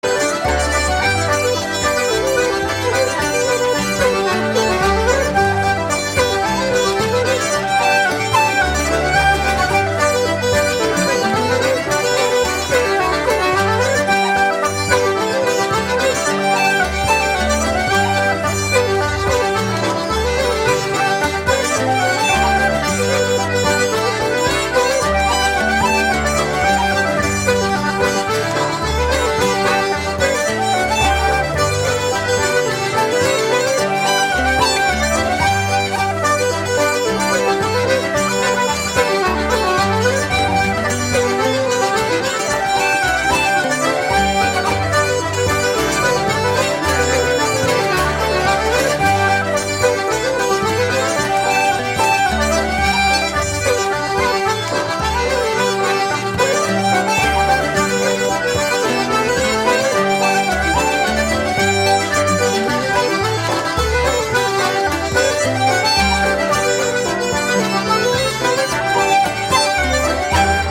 Tonn Teine reel AKA Town Teine